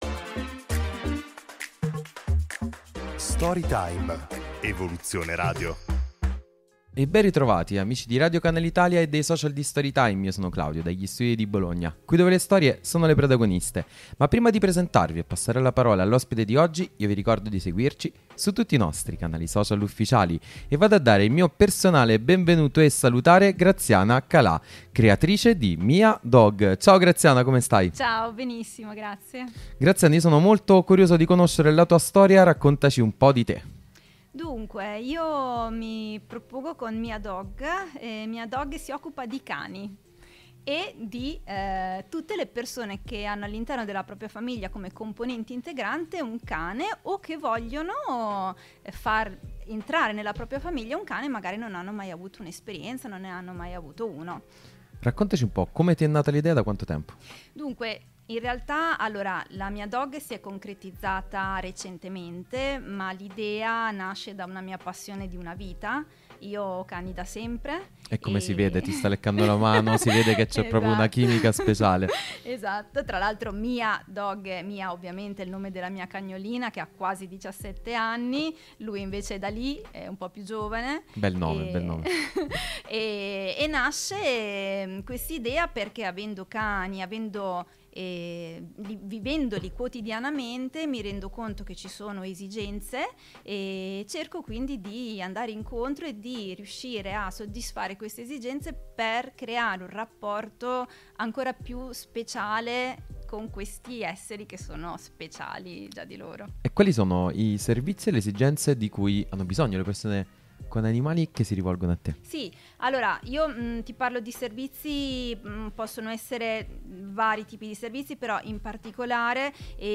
INTERVISTA RADIO CANALE ITALIA – STORY TIME